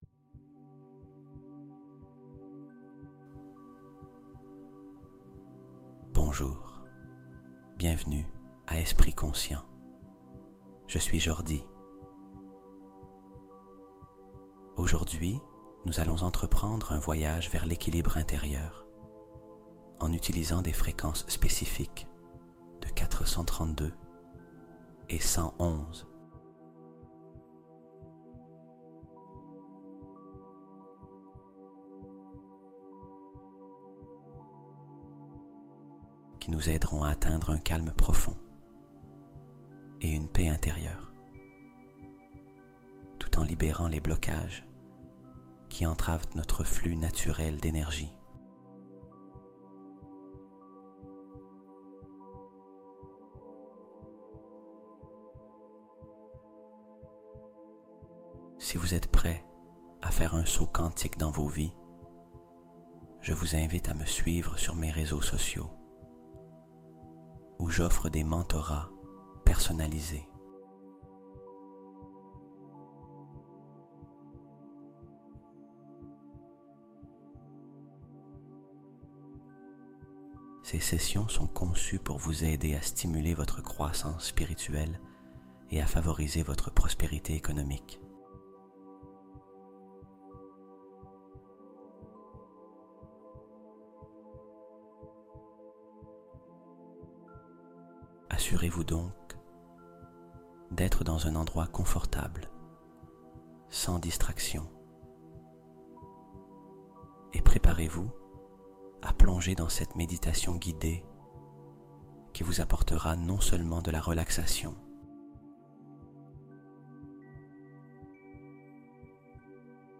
432Hz LA FRÉQUENCE QUI DISSOUT TES BLOCAGES INVISIBLES | Équilibre + Paix + Libération Totale En Une Nuit